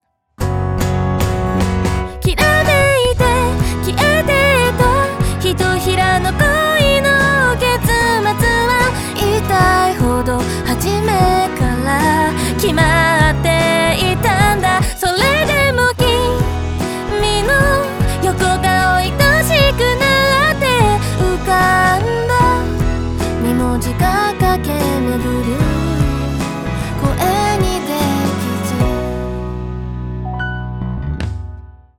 ピッチとタイミングの補正を行いましたが、とても自然な仕上がりです。
RePitchは声のフォルマント（個性）を保つため、「機械的な音」になりにくく直感的なボーカル補正が行えます。
RePitchAfter.wav